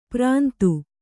♪ prāntu